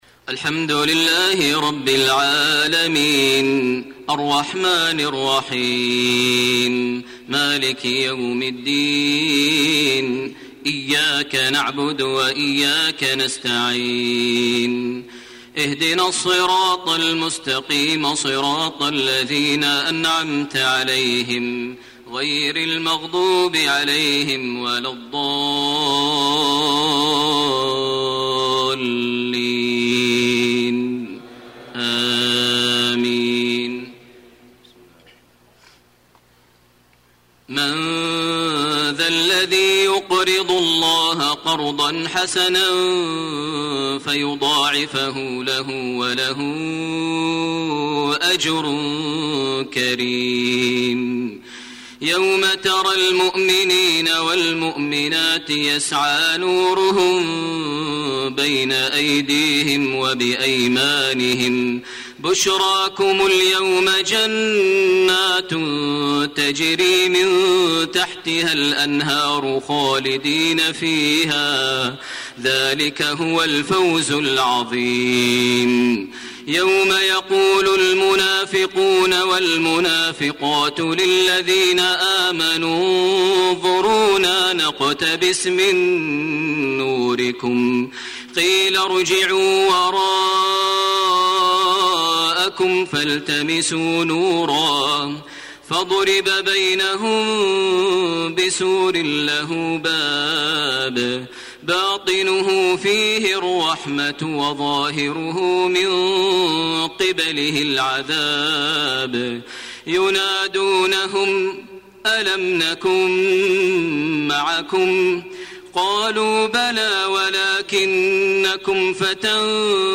صلاة العشاء 2 شعبان 1433هـ من سورة الحديد 11-21 > 1433 هـ > الفروض - تلاوات ماهر المعيقلي